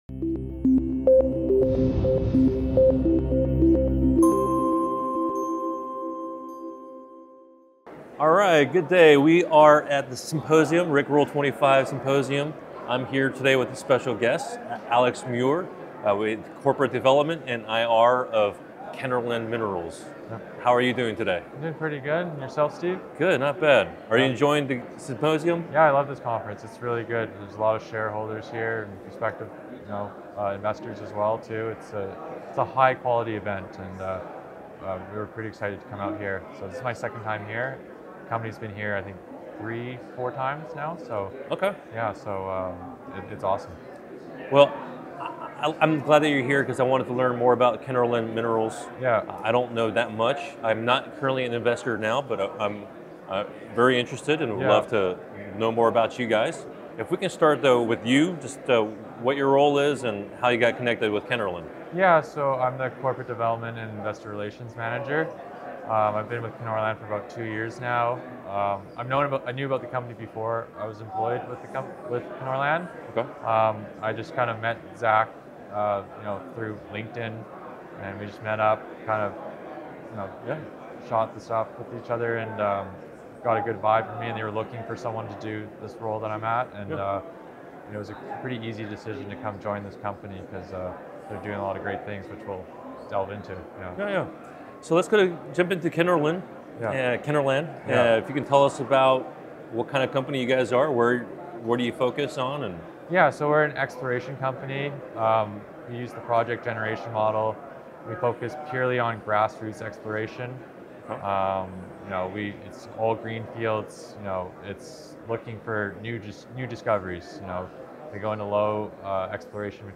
Exploring High-Quality Gold Deposits: An Interview with Kenorland Minerals - Natural Resource Stocks